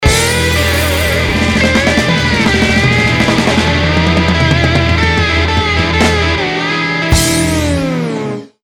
midi coda-импровизаций ударных